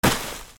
転ぶ素材 落ち葉
/ J｜フォーリー(布ずれ・動作) / J-10 ｜転ぶ　落ちる